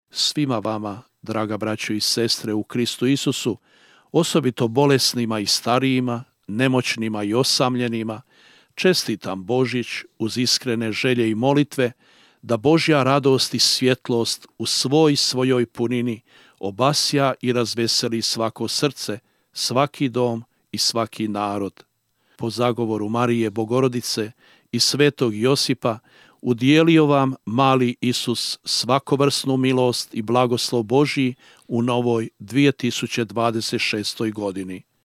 "Neka plamen božićne radosne noći prožme svako srce, svaki dom i cijelu domovinu, naše susjede i sve narode širom svijeta. U svijetu podjela budimo mostovi; u vremenu strašne vike i buke budimo tišina; u vremenu netrpeljivosti i mržnje budimo ljudi – stvorenja Božja, braća i sestre", poručio je biskup Štironja: (